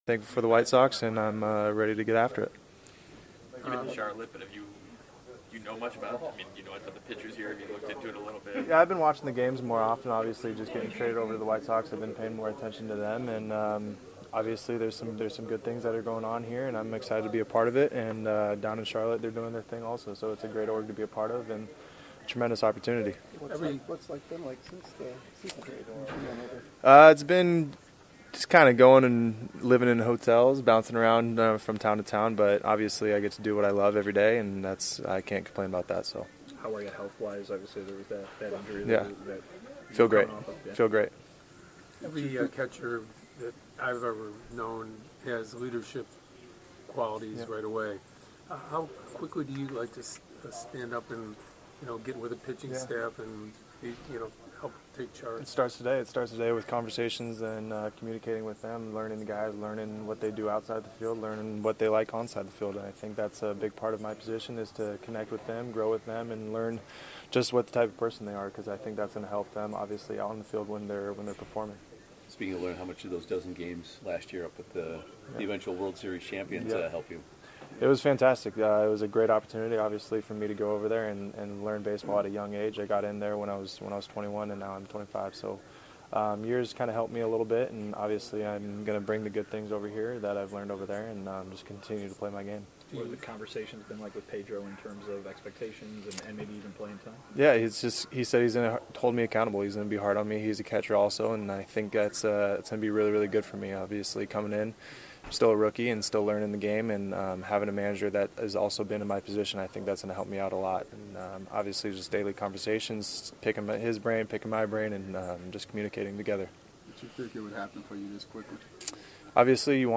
⚾ MLB Classic Interviews